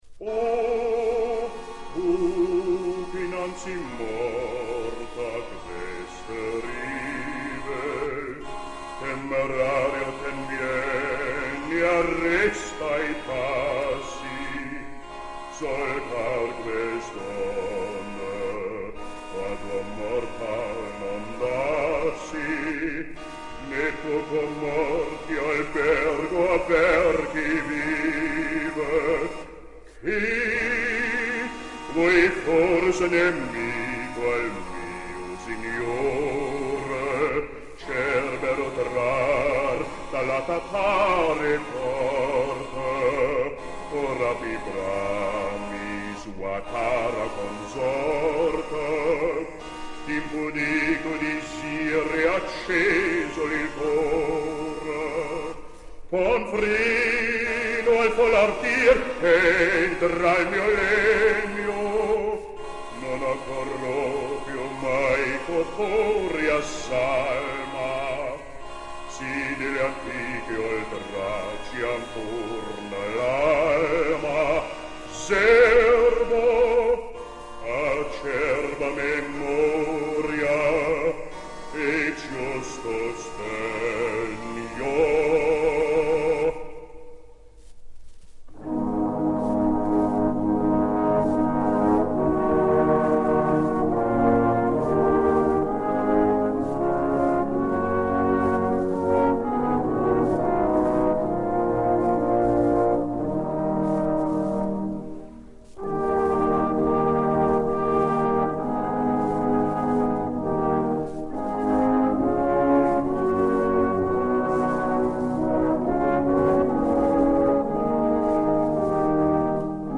registrazione in studio.